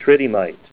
Help on Name Pronunciation: Name Pronunciation: Tridymite + Pronunciation
Say TRIDYMITE